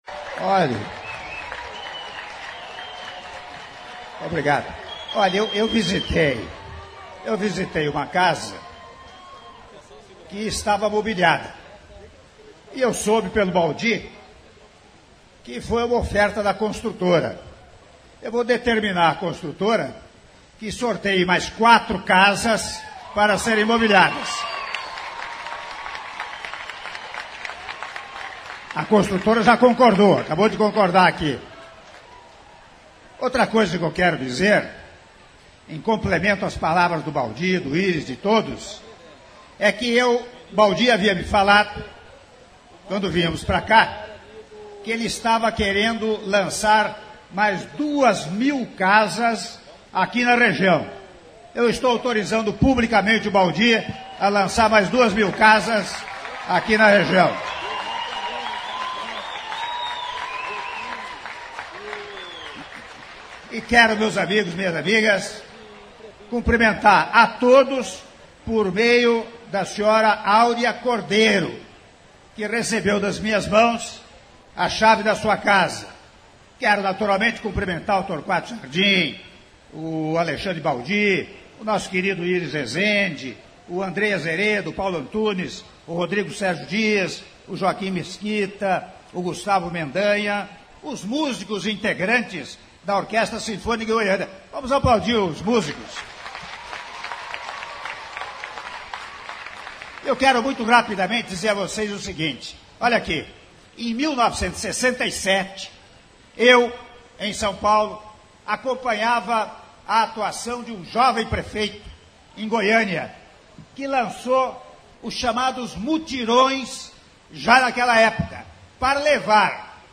Áudio do discurso do presidente da República, Michel Temer, durante cerimônia de entrega de 1.080 unidades habitacionais do Residencial Jardim do Cerrado VI - Goiânia/GO - (06min39s)